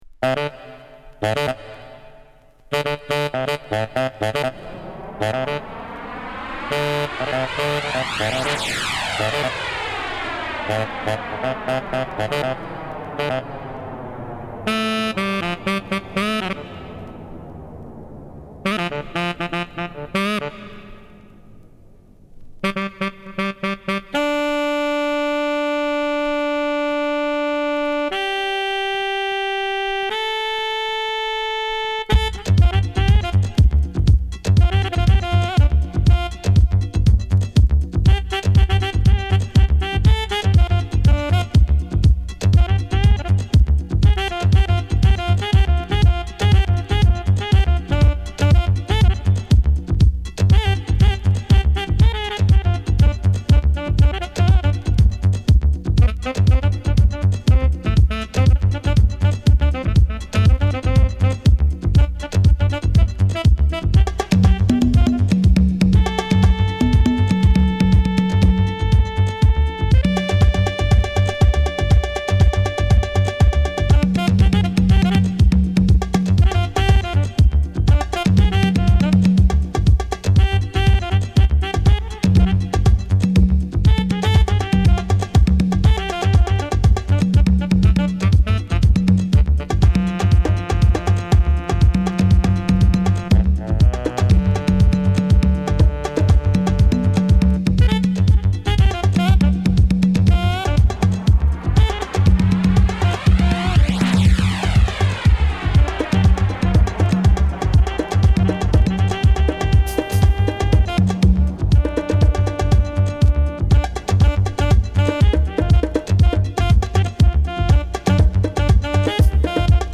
Dance Track , Deep House
Tribal